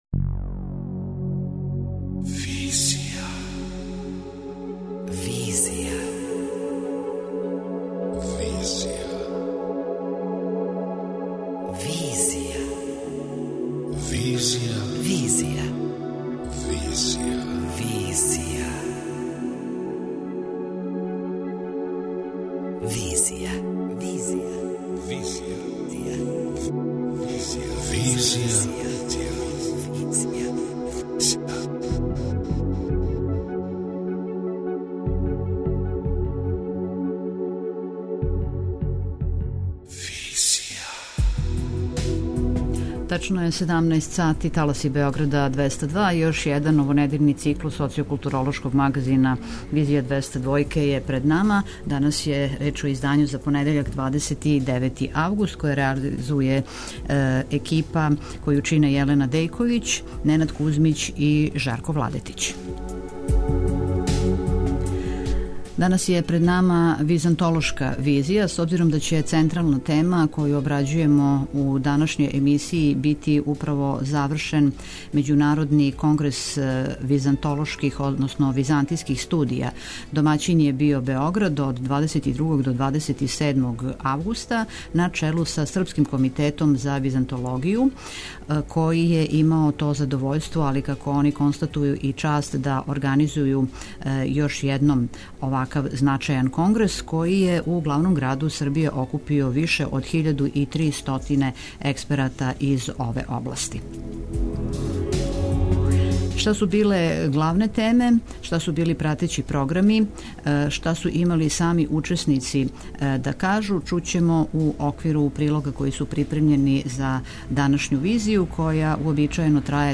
У данашњој Визији чућете утиске учесника као и размишљања о будућности византологије.